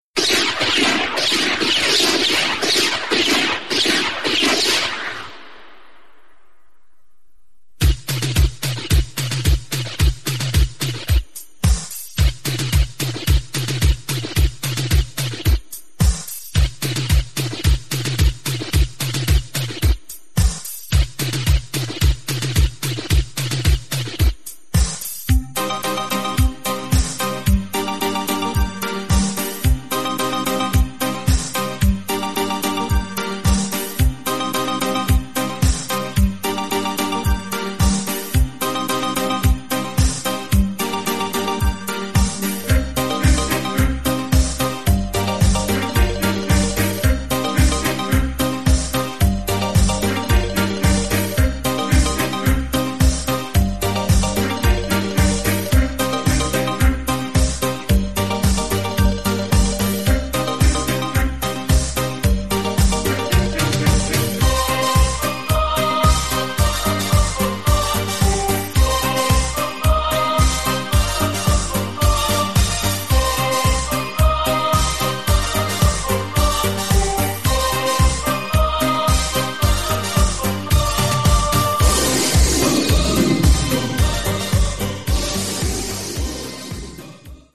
format: 5" compilation
mixed & remixed by various DJs